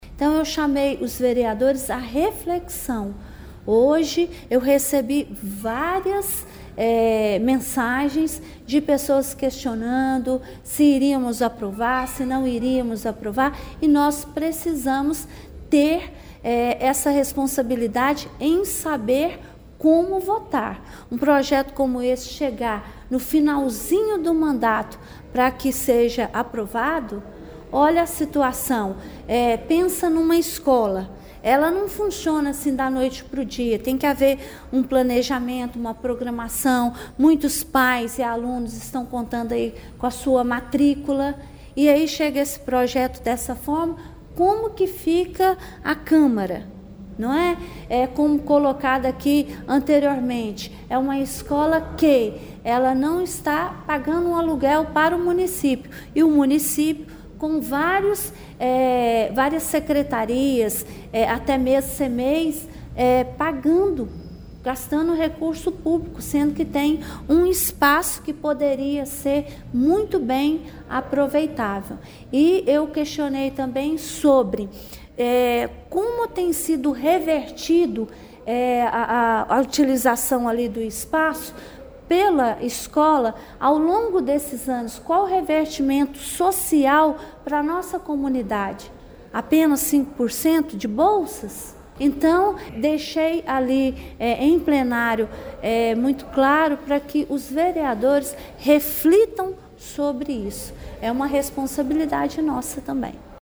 O Portal GRNEWS acompanhou a reunião ordinária da Câmara Municipal de Pará de Minas nesta terça-feira, 03 de dezembro.